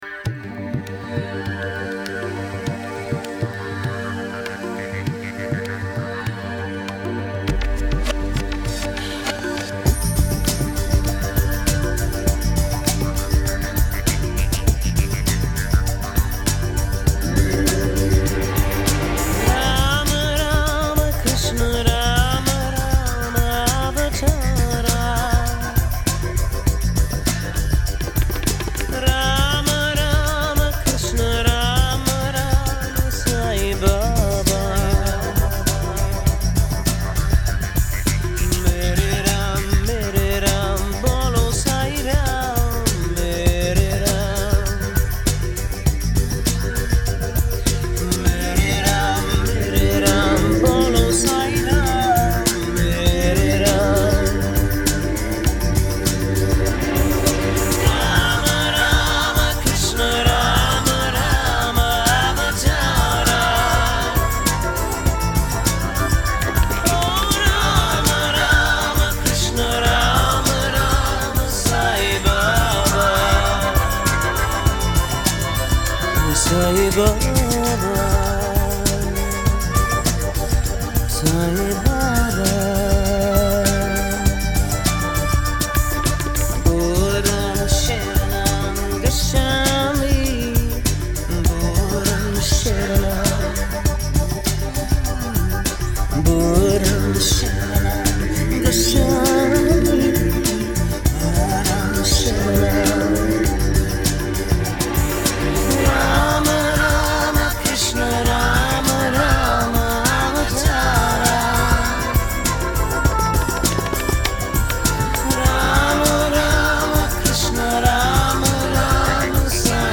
Индийские бажданы в шедевральной аранжировке.
Стиль: Ethnic New Age UK / USA